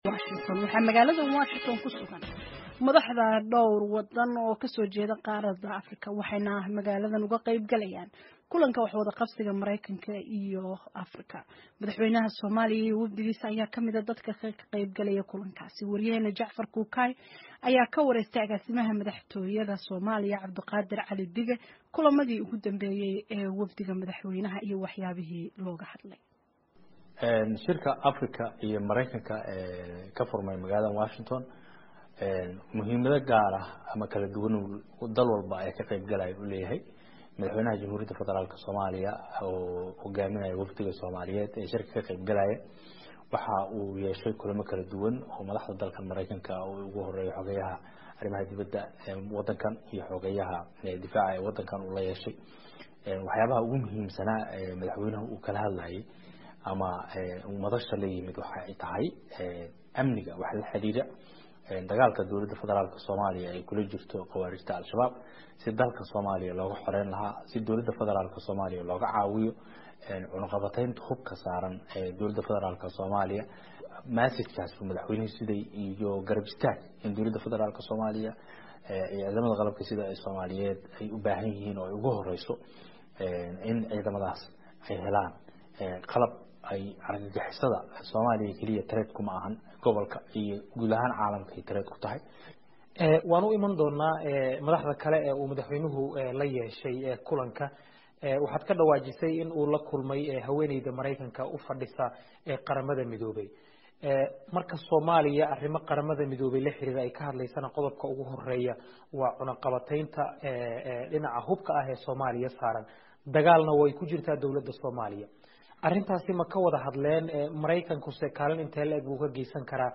Wareysi: Safarka madaxweyne Xasan Sheekh ee Mareykanka